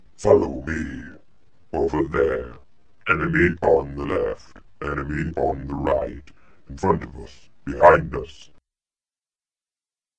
描述：外星狙击手有一部分是机器人，以帮助它瞄准，因此它必须有一个机器人声音调制器来说话
标签： 狙击手 订单 阵容 外星人 机器人 命令
声道立体声